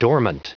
Prononciation du mot dormant en anglais (fichier audio)
Prononciation du mot : dormant